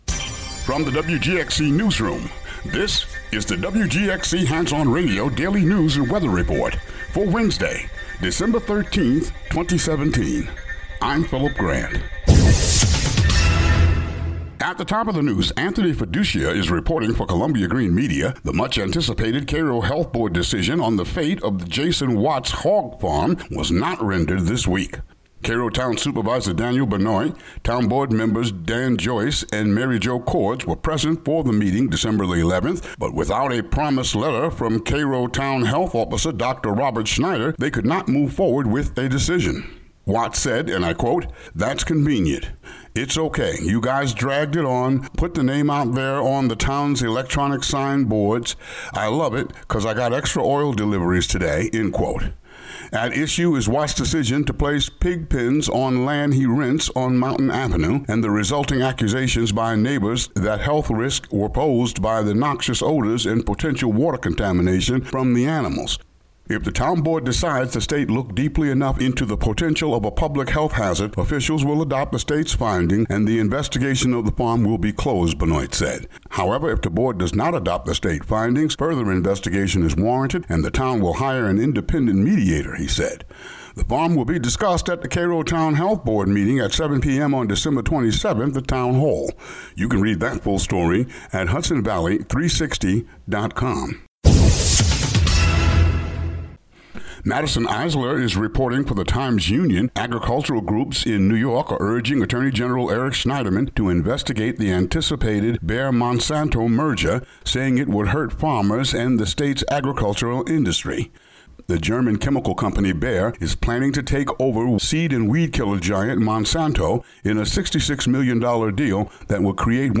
WGXC Local News